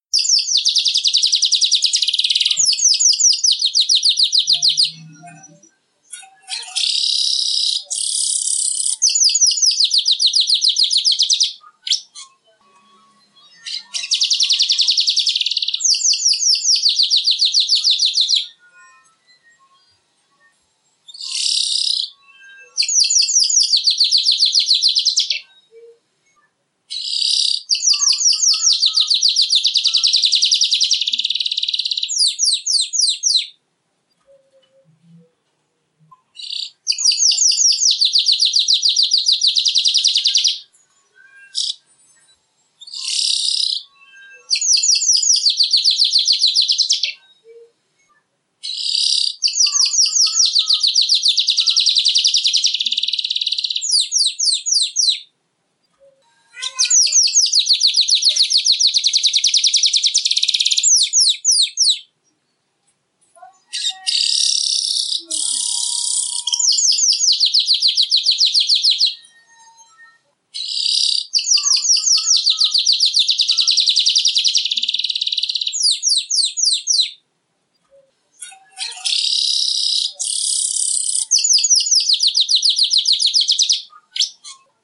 Download Suara Burung Manyar Gacor
Pasalnya suara kicau manyar terdengar nyaring dan bervareasi, tak heran jika banyak orang tertarik untuk memelihara atau sekedar mencari suaranya.